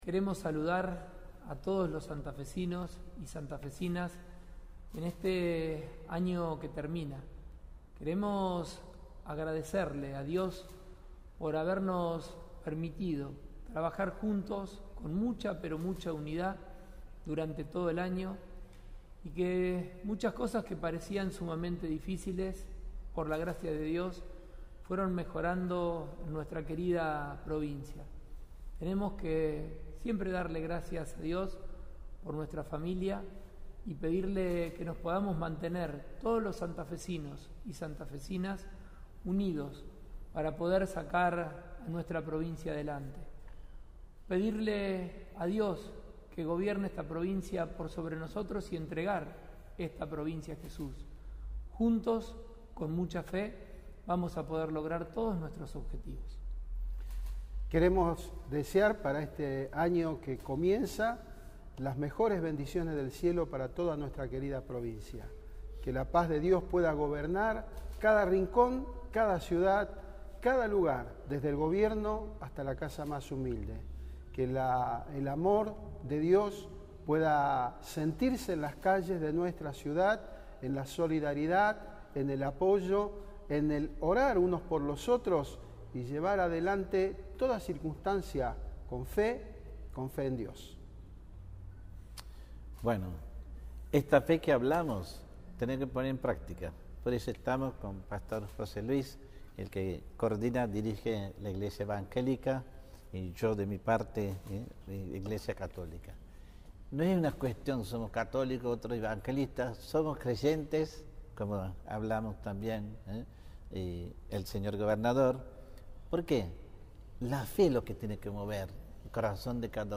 El gobernador grabó un mensaje de fin de año